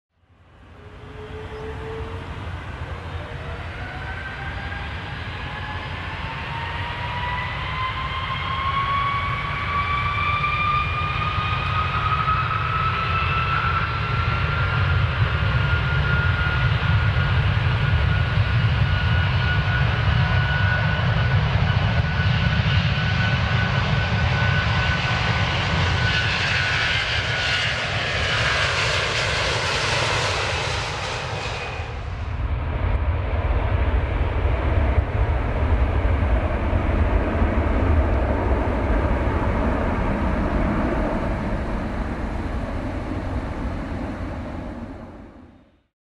دانلود صدای پرواز یا بلند شدن هواپیما 2 از ساعد نیوز با لینک مستقیم و کیفیت بالا
جلوه های صوتی